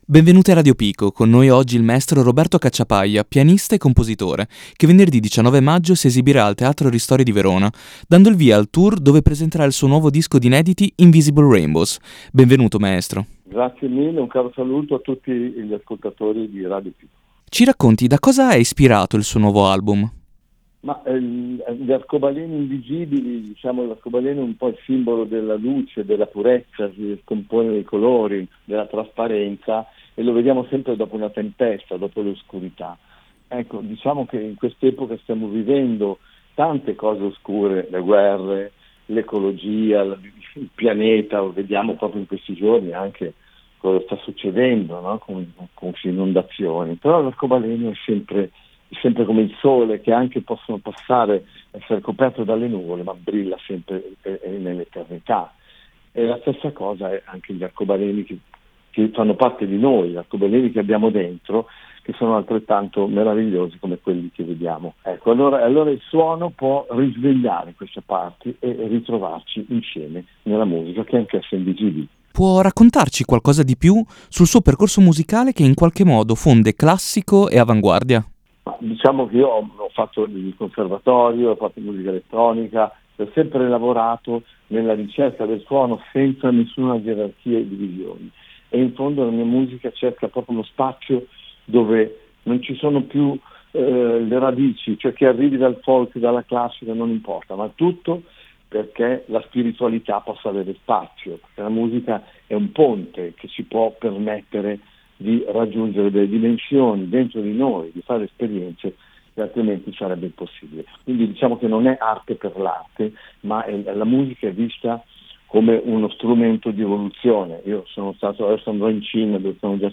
La nostra intervista al Maestro Roberto Cacciapaglia: